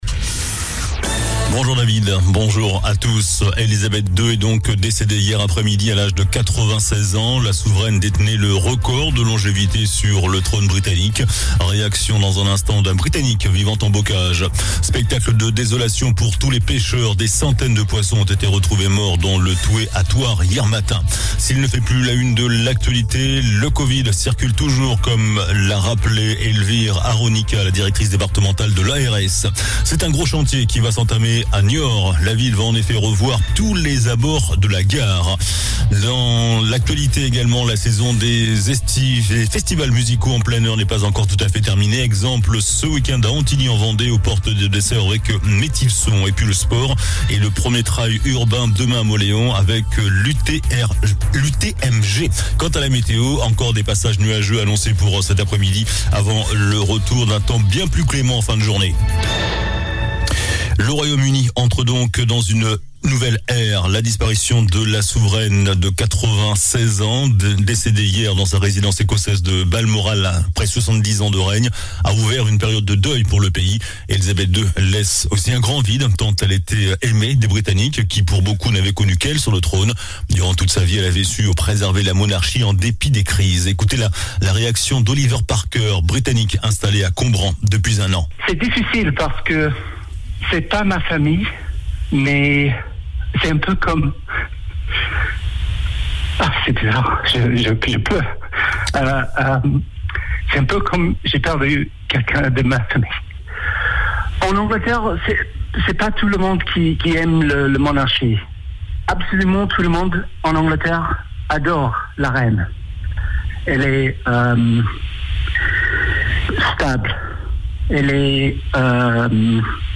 JOURNAL DU VENDREDI 09 SEPTEMBRE ( MIDI )
L'info près de chez vous. Elisabeth II est décédée hier après-midi à l’âge de 96 ans. Réaction d'un britannique demeurant en deux-sèvres. Des centaines de poissons retrouvés morts dans le Thouet à Thouars ( photo ).